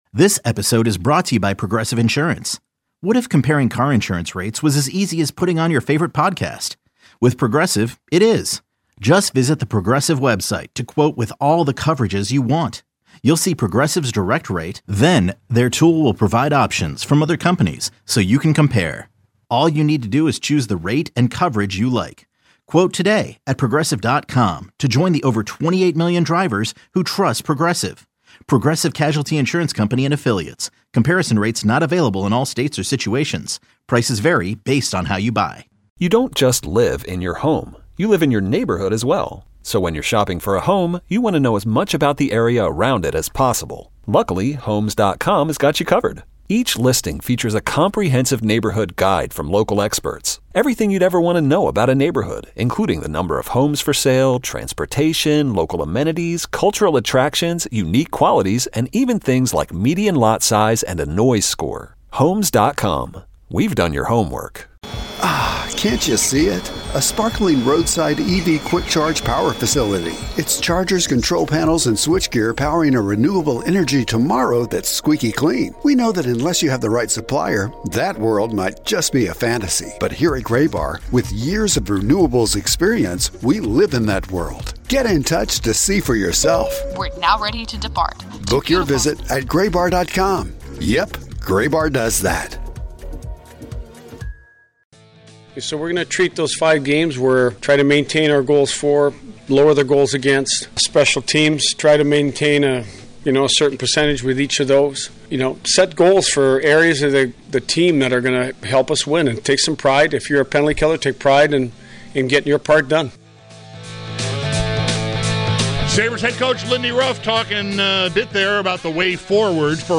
takes your calls